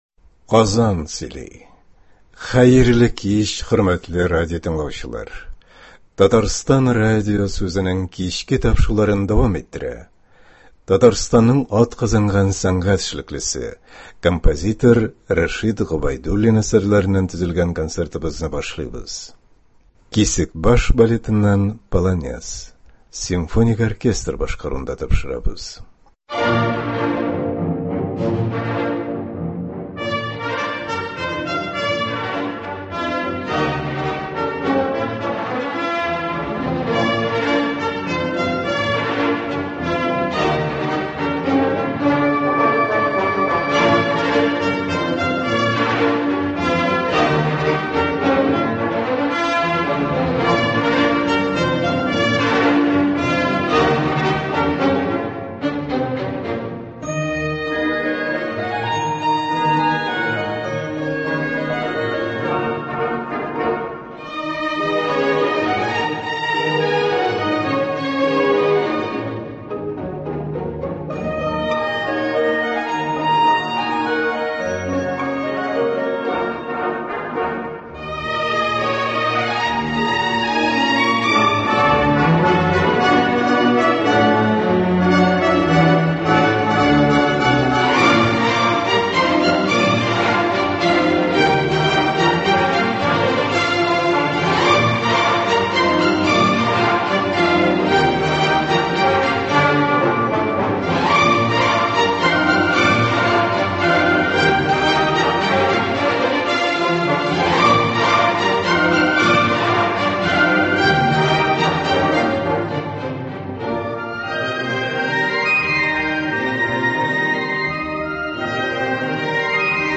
Концерт (23.06.21)